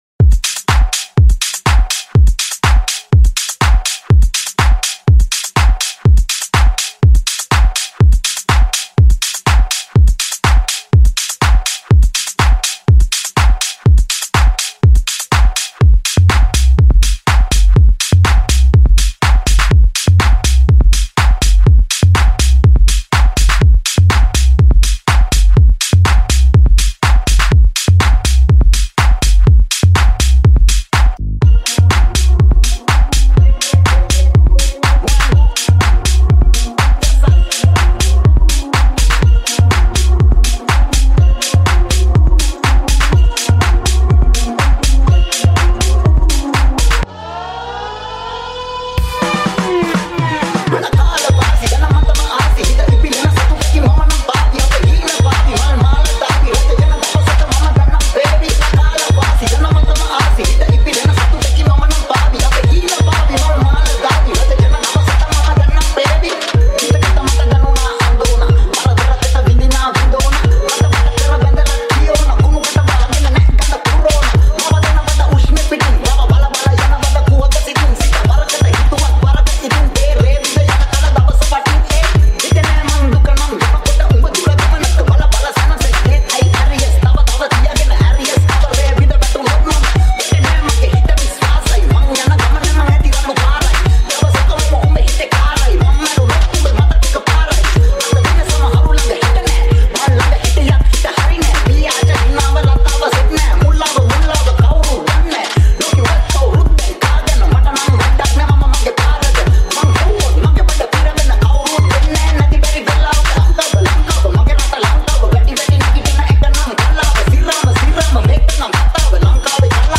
Genre - Tech House
BPM - 123